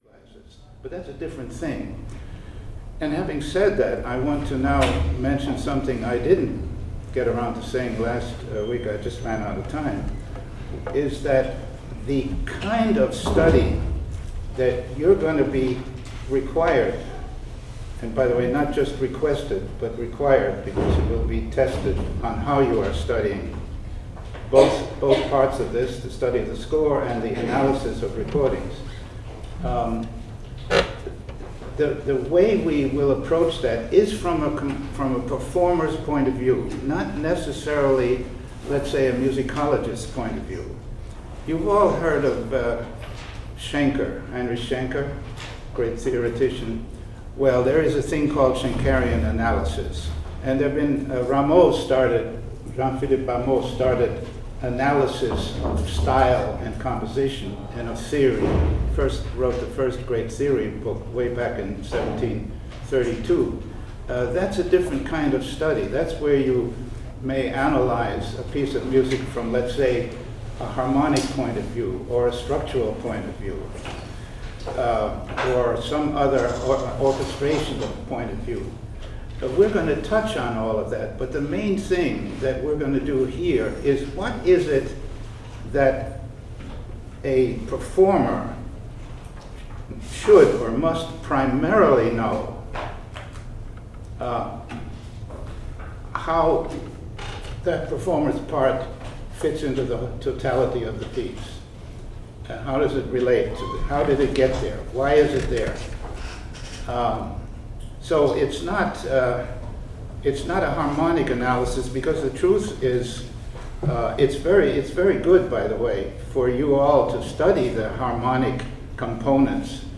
Next, I have some brief excerpts recorded from lectures he gave during his residency at UW-Madison (mentioned in the above quote) in fall 2005.
• “Analysis for Performers,” The Compleat Performer Course, September 13, 2005